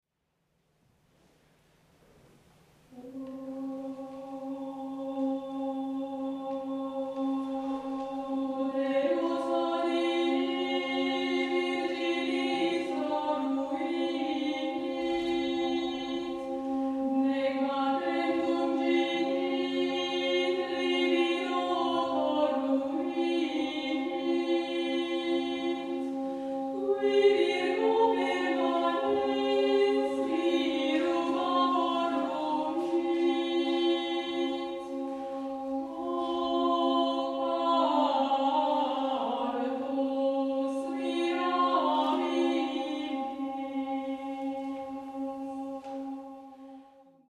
monodia e polifonia medievali
Uterus hodie BNF, Lat. 3719, f. 38v, scuola di St. Martial de Limoges, versus, XII sec. testo